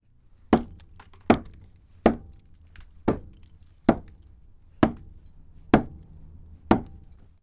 乡村 " 锤炼钉子
描述：重复锤击指甲的声音